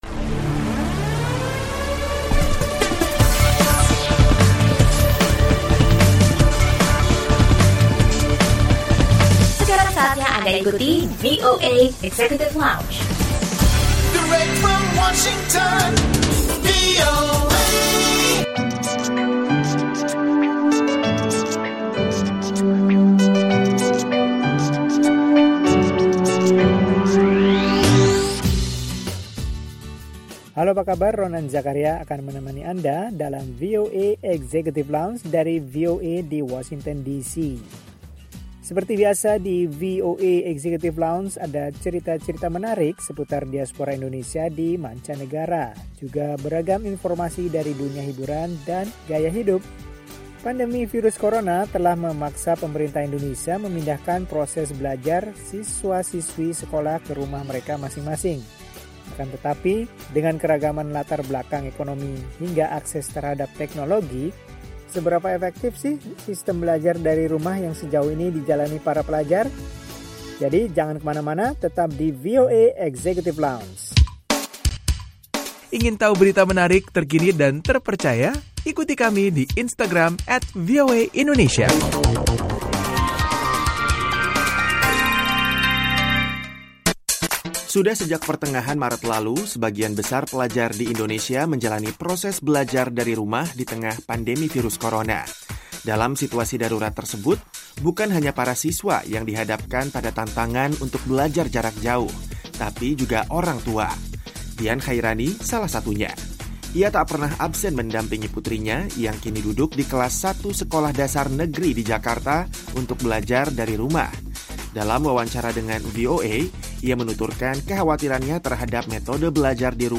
Oborlan mengenai dampak dari berlakunya proses Pembelajaran Jarak Jauh di Indonesia.